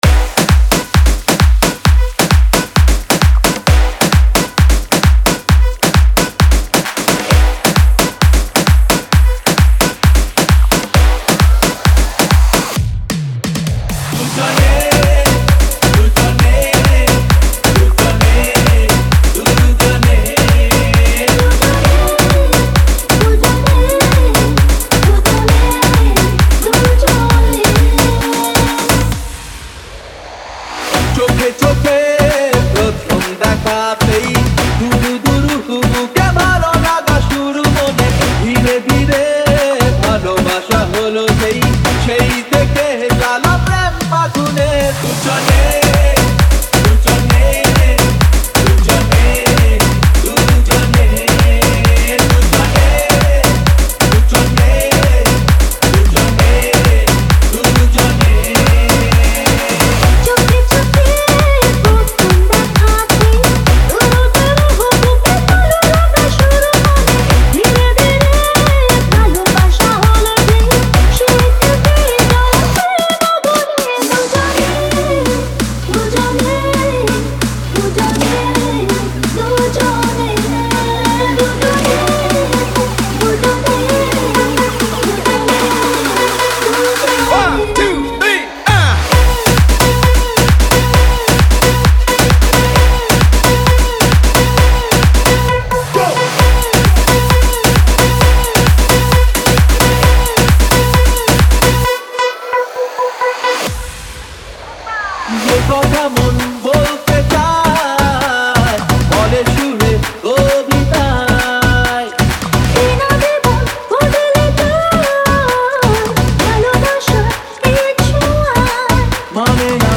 2025 Tollywood Single Remixes Song Name